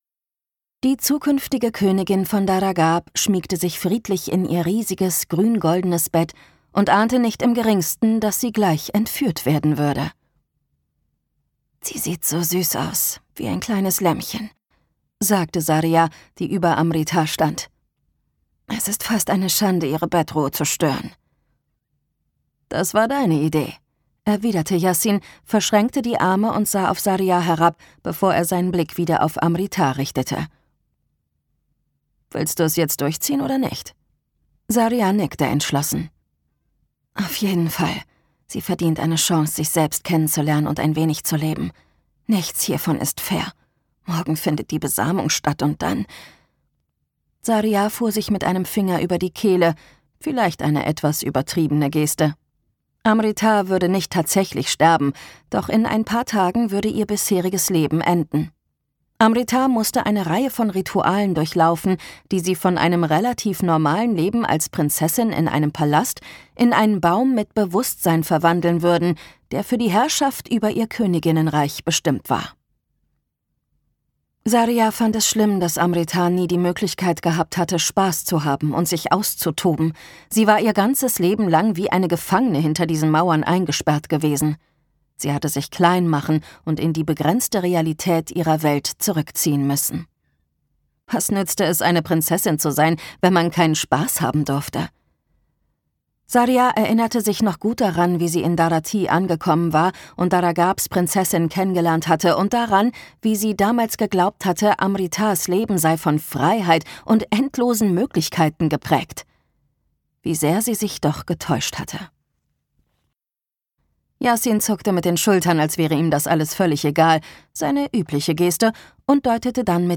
Dance of Stars and Ashes - Nisha J. Tuli | argon hörbuch
Gekürzt Autorisierte, d.h. von Autor:innen und / oder Verlagen freigegebene, bearbeitete Fassung.